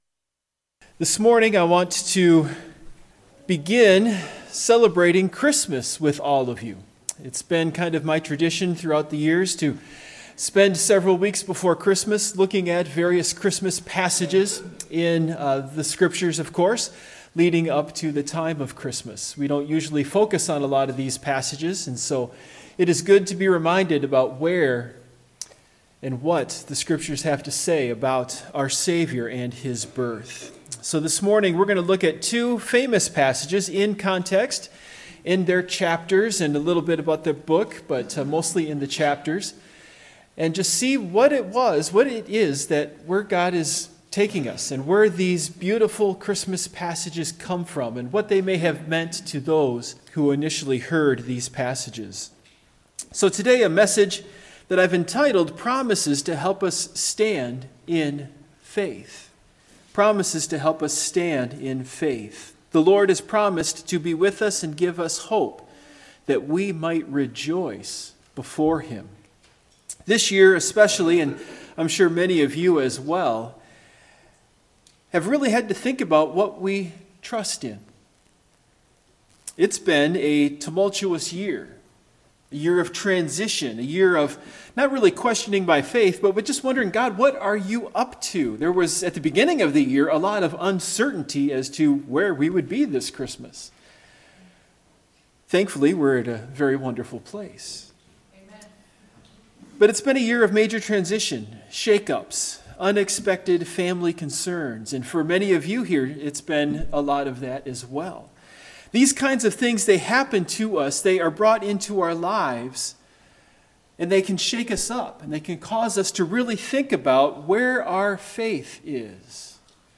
Passage: Isaiah 7:14, Isaiah 9:1-7 Service Type: Morning Worship Topics